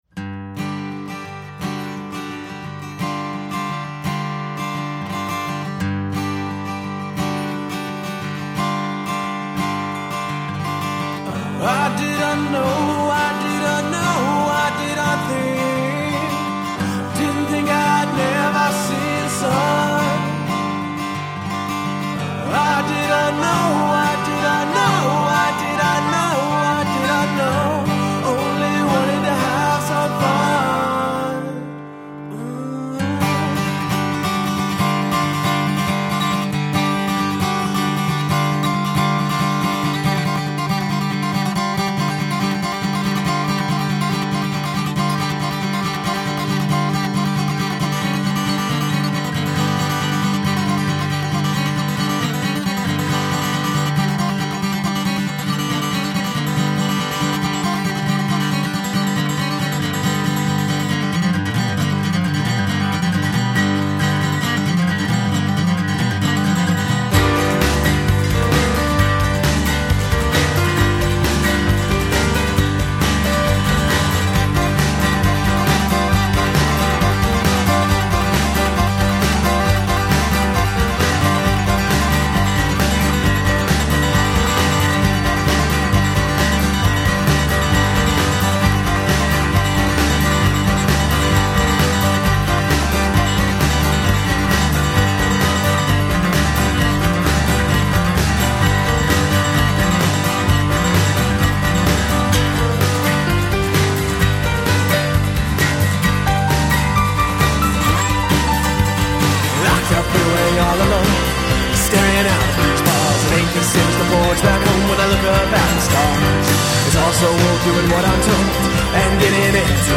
Guitar, Violin, Mandolin, Vocals
Drums
Piano
Upright Bass